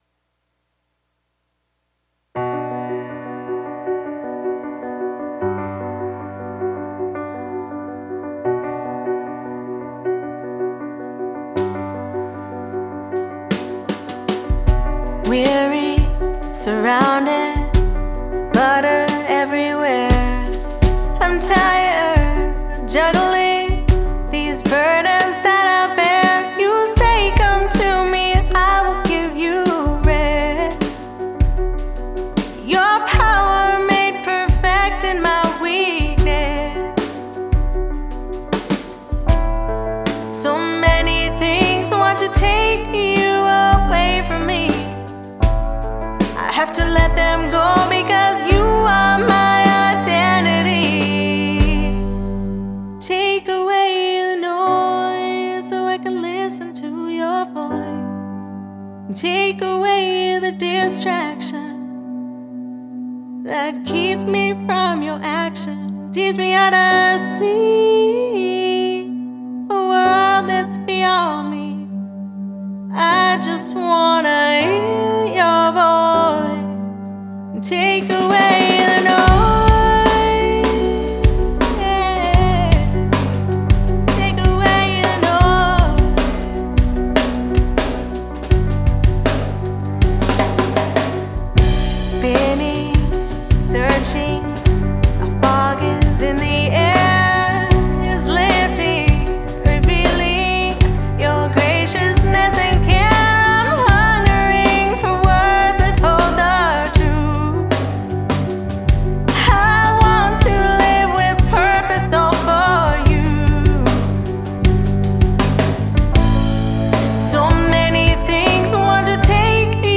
keys
guitars
drums